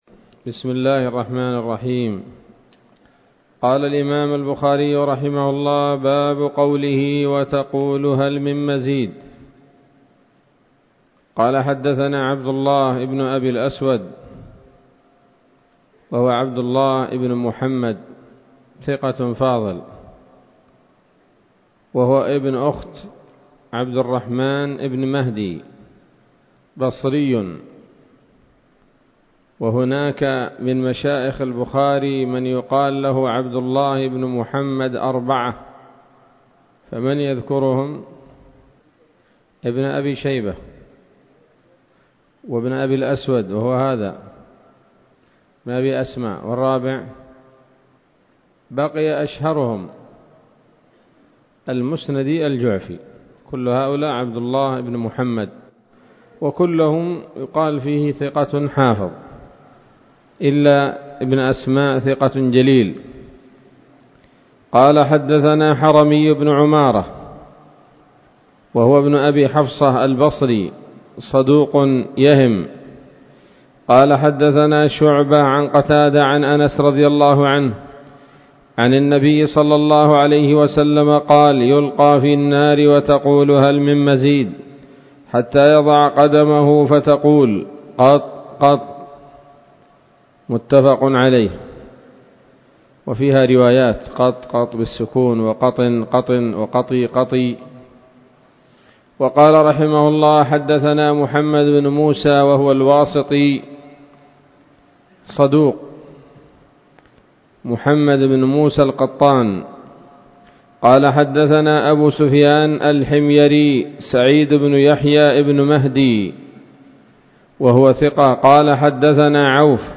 الدرس الثامن والثلاثون بعد المائتين من كتاب التفسير من صحيح الإمام البخاري